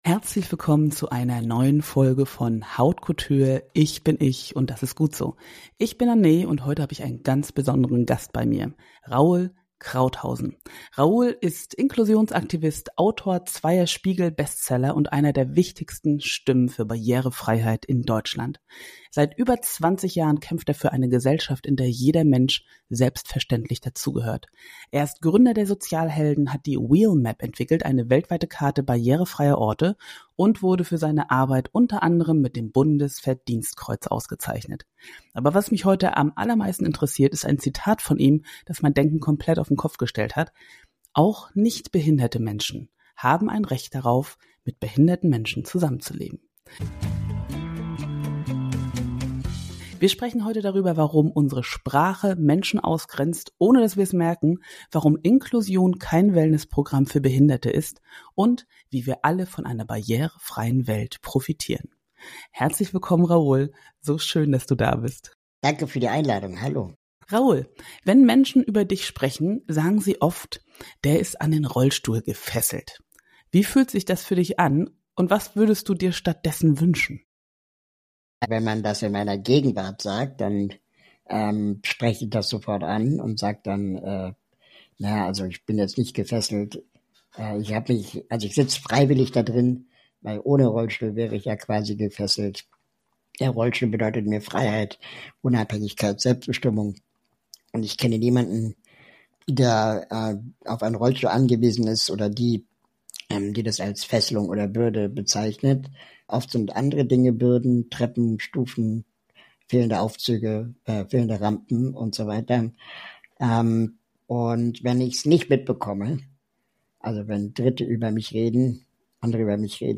Ein Gespräch, das deine Perspektive für immer verändern wird – und dir zeigt, dass eine inklusive Welt nicht nur gerecht, sondern auch reicher für uns alle ist.